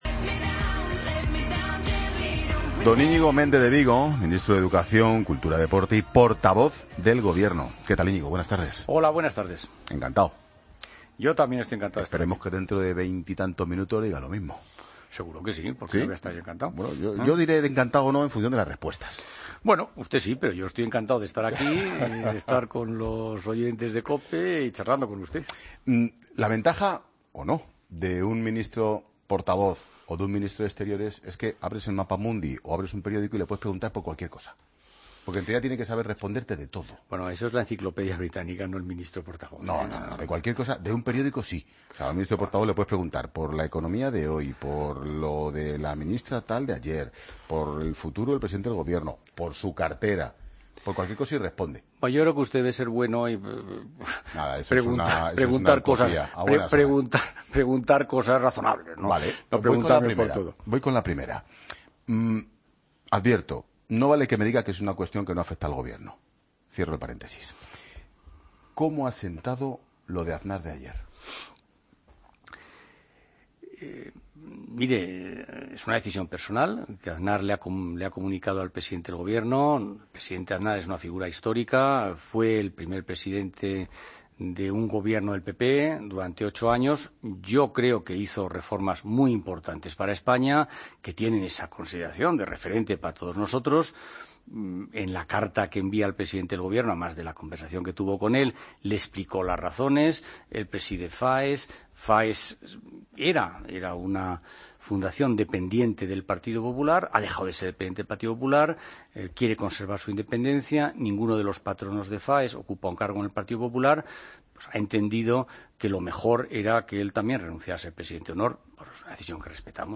Escucha la entrevista al Ministro de Educación, Cultura y Deporte, Íñigo Méndez de Vigo, en 'La Tarde'